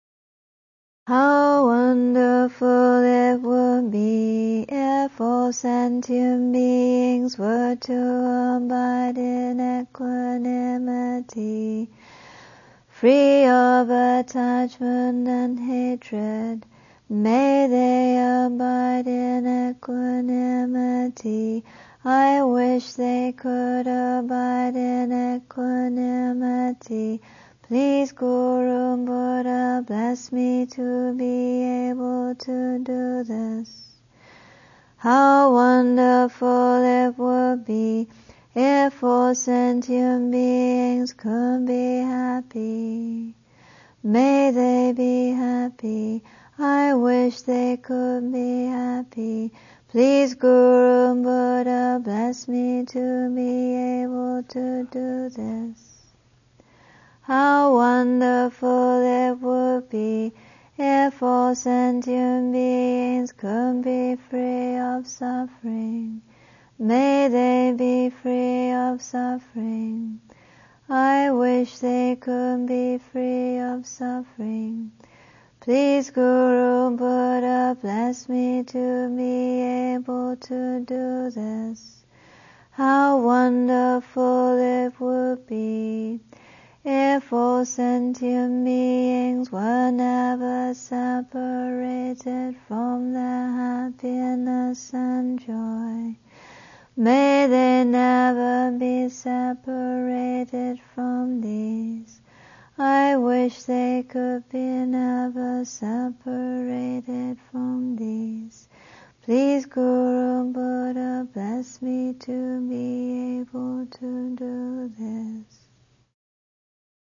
English and Tibetan chanting
Mandarin chanting